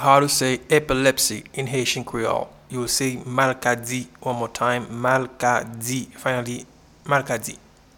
Pronunciation and Transcript:
Epilepsy-in-Haitian-Creole-Malkadi.mp3